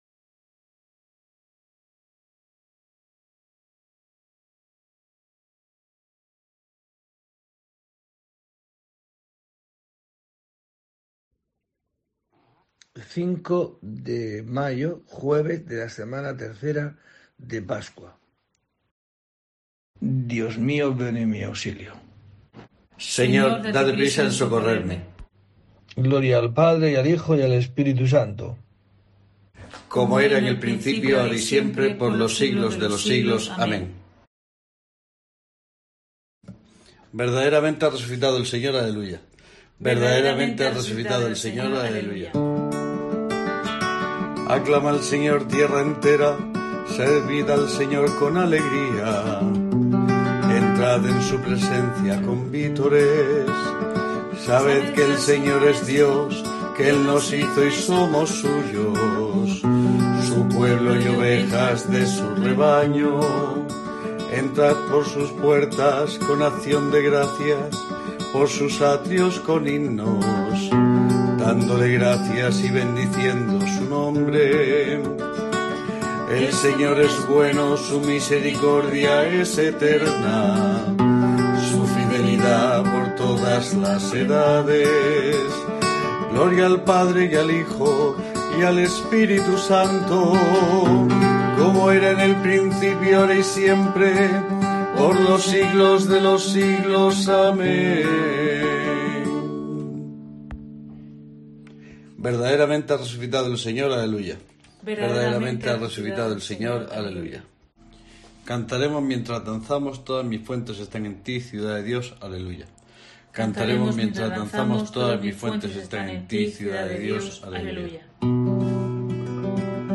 05 de mayo: COPE te trae el rezo diario de los Laudes para acompañarte